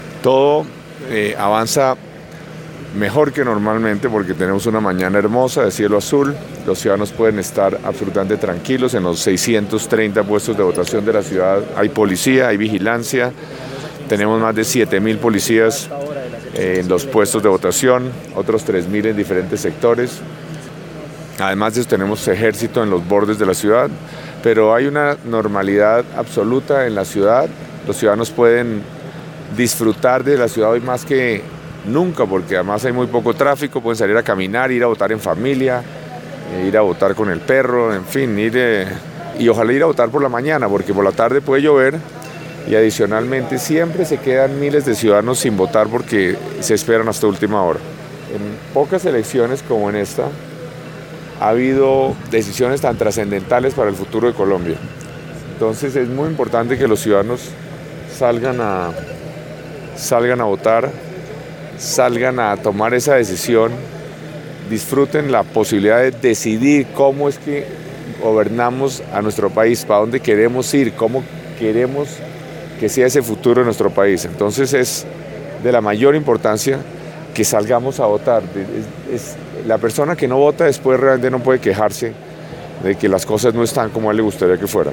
El alcalde mayor Enrique Peñalosa comentó al momento de votar sobre la tranquilidad en la que se ha desarrollado las elecciones en la ciudad. Con un día soleado, invito a los residentes en la ciudad a ser protagonistas de la democracia y ejercer su derecho para proponer el país que queremos.
alcalde-de-Bogota.mp3